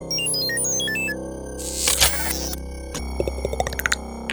Звуки управления пультом